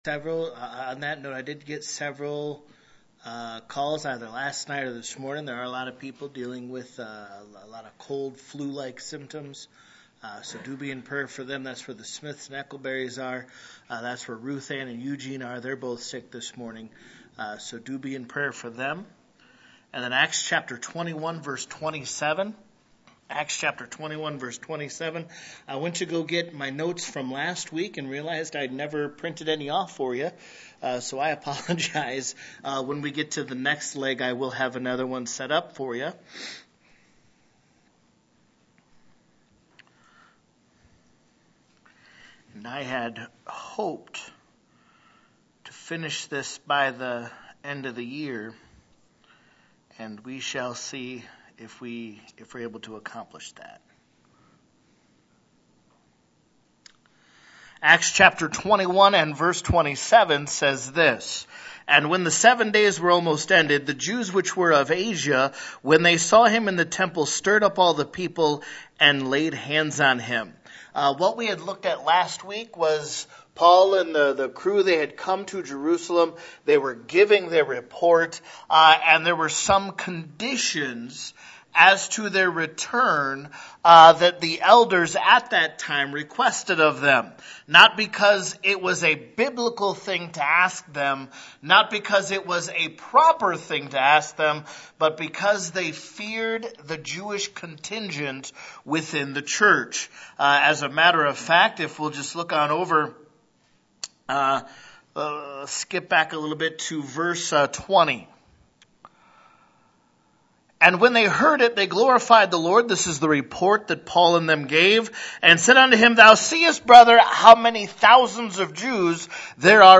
December 2025 - Weekly Sunday Services This page presents the Sunday School lessons recorded at Home Missionary Baptist Church during our Sunday Services.